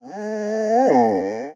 Minecraft Version Minecraft Version latest Latest Release | Latest Snapshot latest / assets / minecraft / sounds / mob / wolf / cute / whine.ogg Compare With Compare With Latest Release | Latest Snapshot
whine.ogg